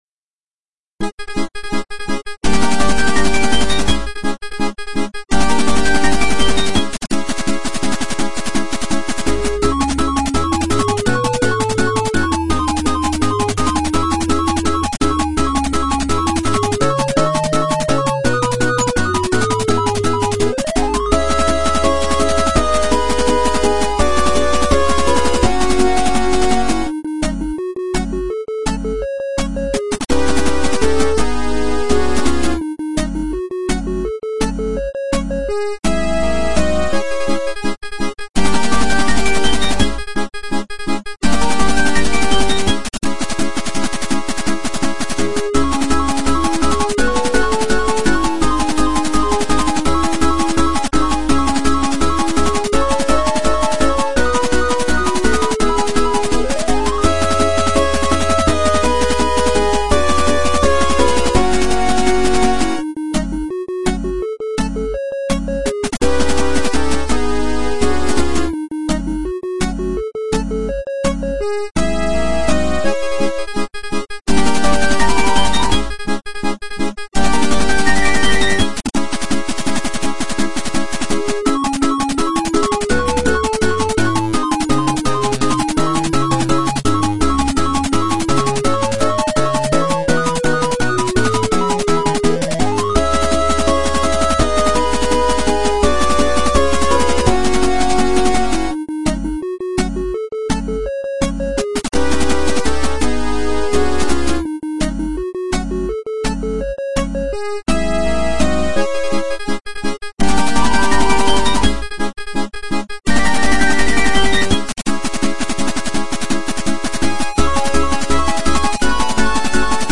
Дешево и сердито, и вполне себе бодрый чиптюн вышел.
Я думаю, что это всё-таки больше подходит на погоню.